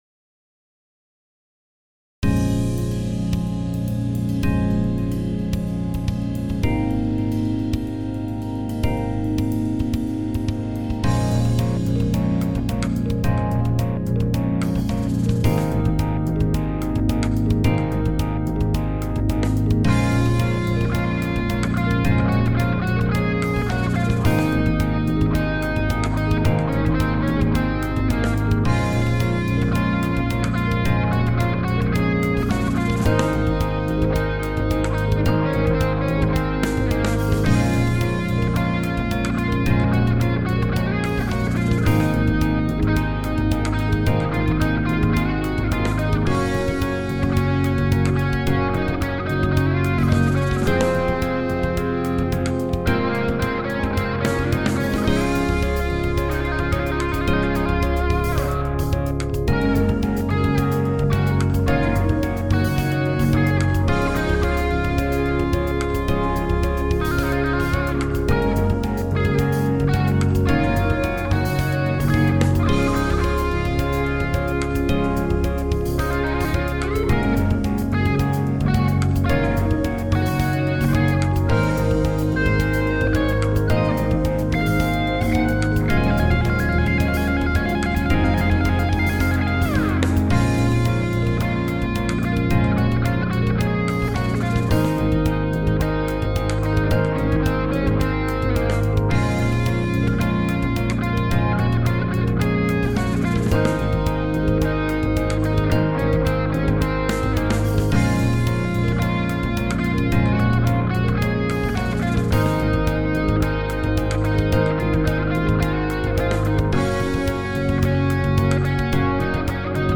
Version 2 – Lounge Mix (geänderter Rhythmus)